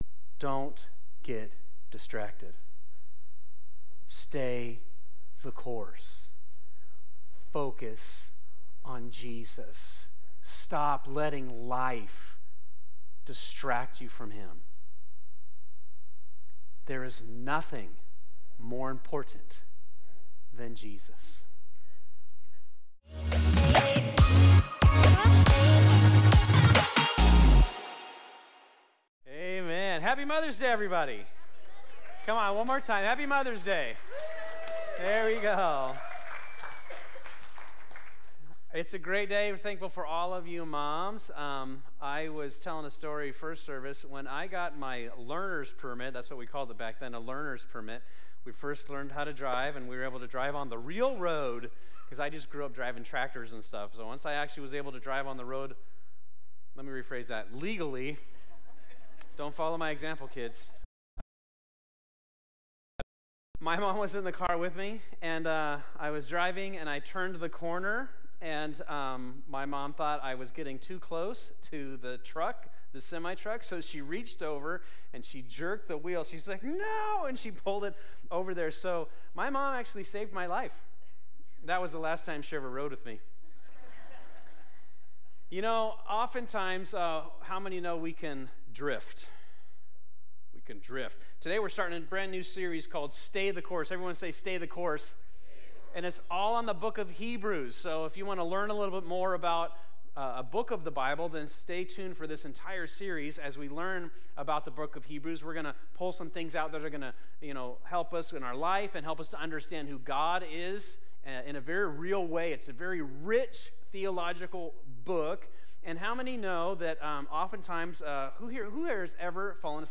This is part 1 of “Stay the Course,” our sermon series at Fusion Christian Church on the book of Hebrews.
2024 Stay the Course Faith Sunday Morning This is part 1 of "Stay the Course